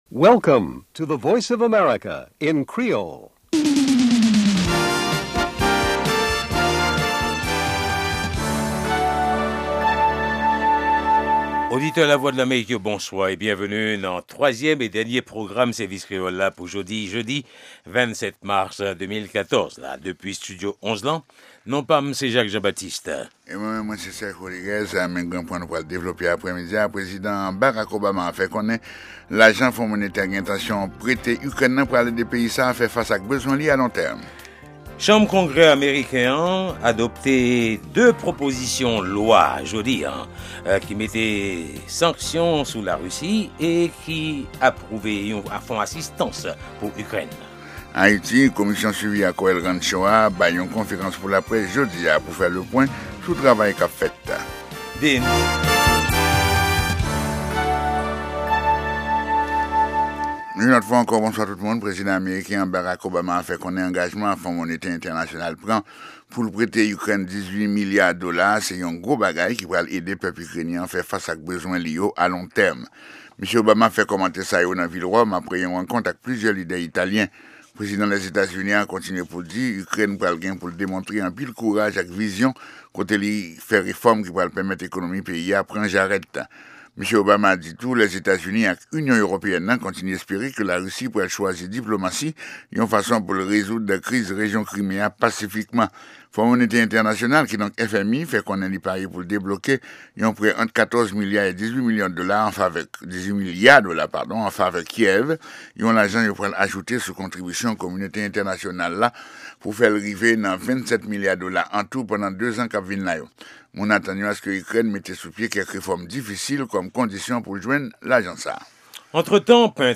e 3èm e dènye pwogram jounen an, avèk nouvèl tou nèf sou Lèzetazini, Ayiti ak rès mond la. Pami segman ki pase ladan yo e ki pa nan lòt pwogram yo, genyen Lavi Ozetazini ak Nouvèl sou Vedèt yo.